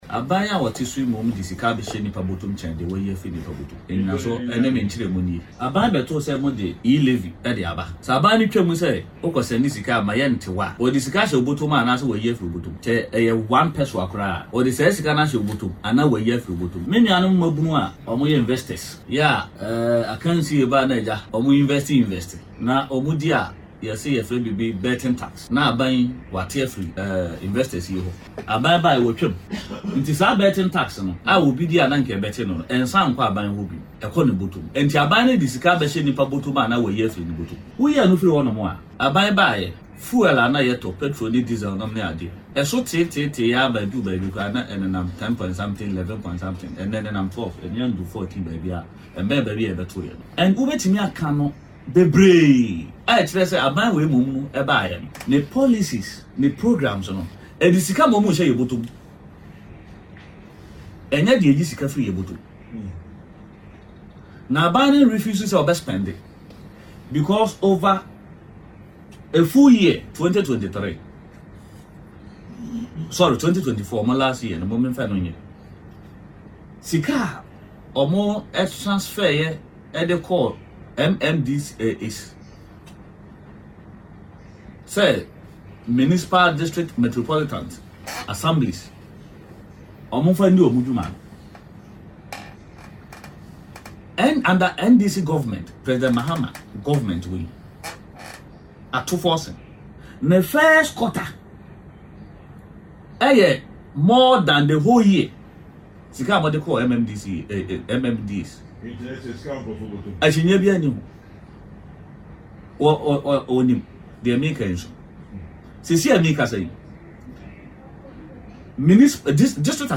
Speaking on Peace FM’s “Kokrokoo” morning show, Azumah argued that the John Mahama-led NDC government has provided more relief to Ghanaians than the previous administration.